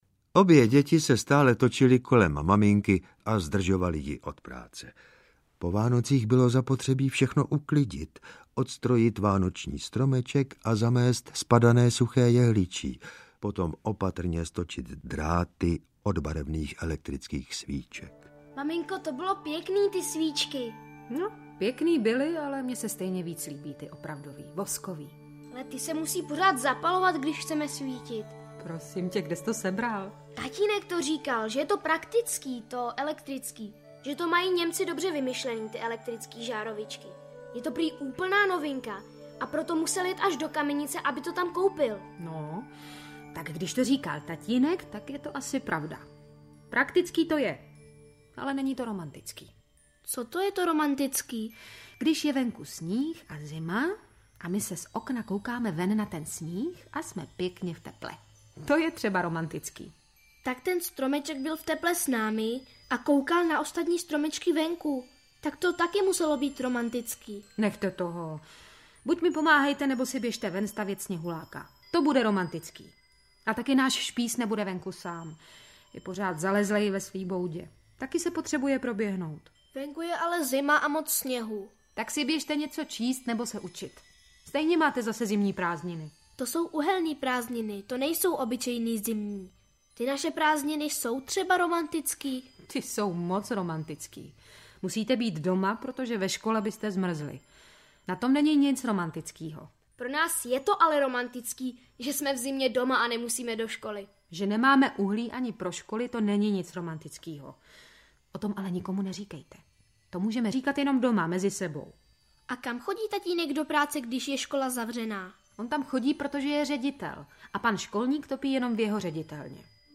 Blondi audiokniha
Ukázka z knihy
• InterpretMichal Pavlata, Ivan Trojan, Jan Hartl, Jiří Dvořák, Simona Stašová, Naďa Konvalinková, Vladimír Javorský